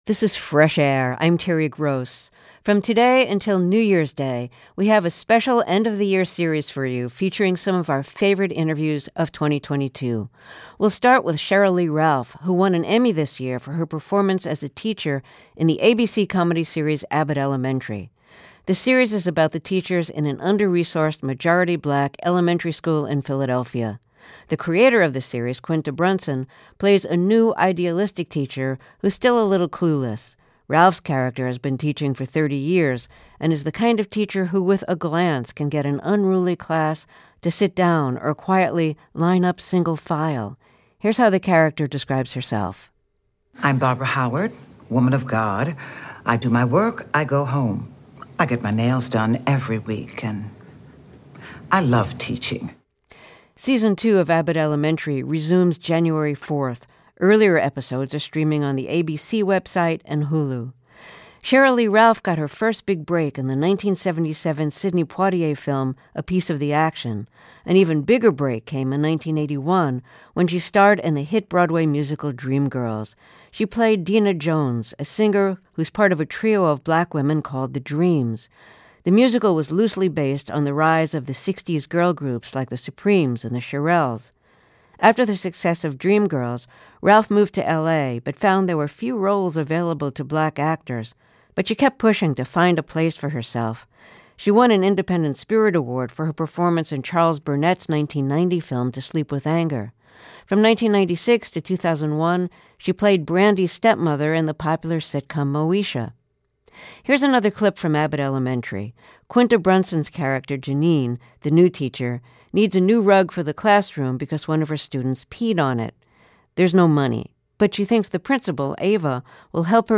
Our series of favorite interviews from 2022 kicks off with Sheryl Lee Ralph.